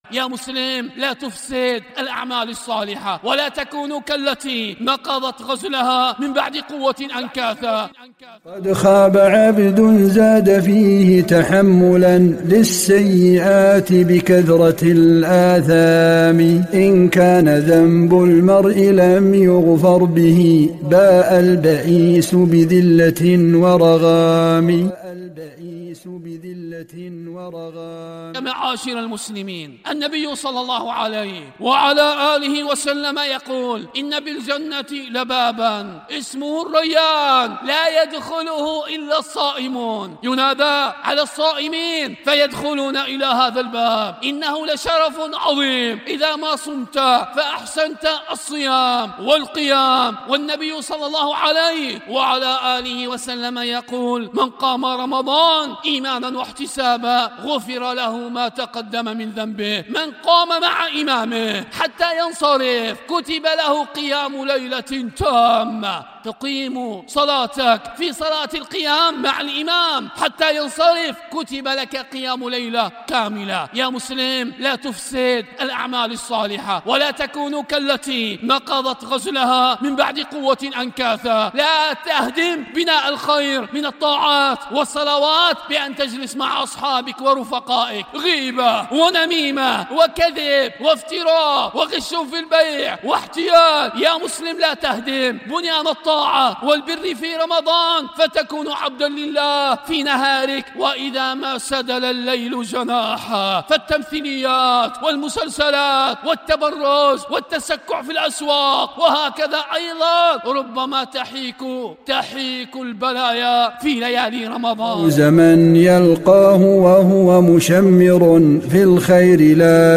🔊 موعظة: